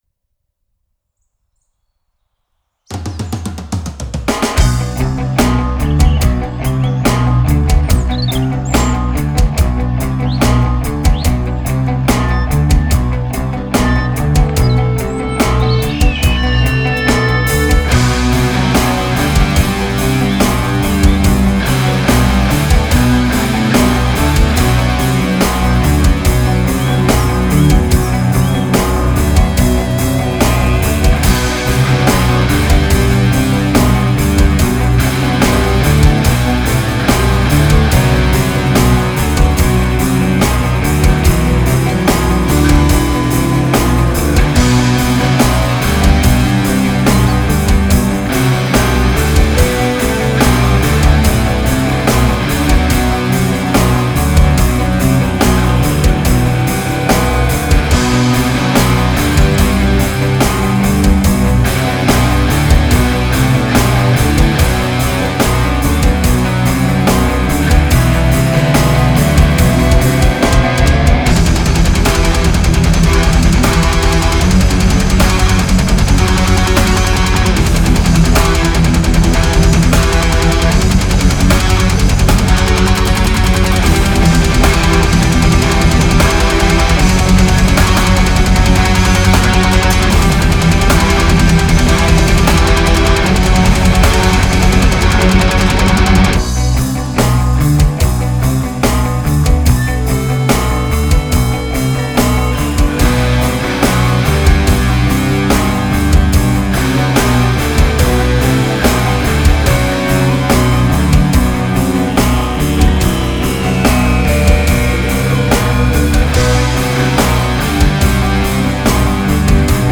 شاهکار بی نظیر متال.